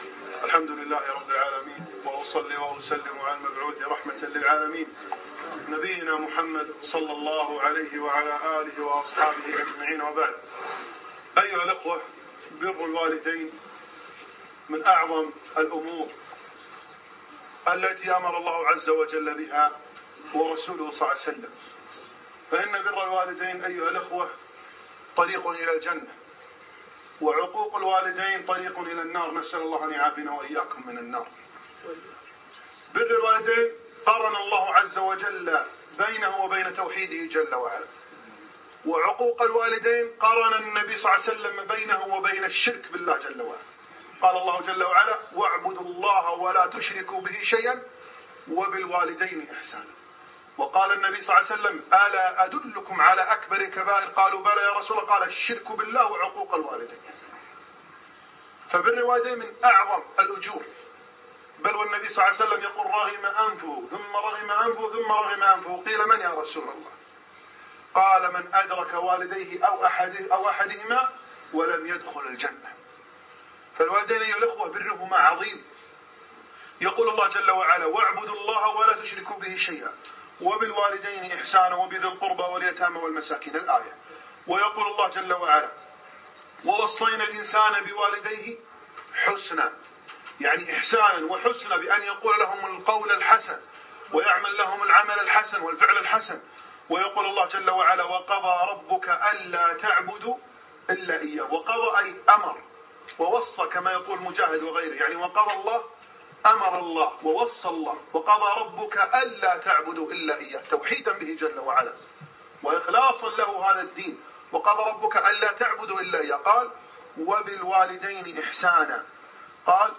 إلى أصحاب حفلات المدارس - كلمة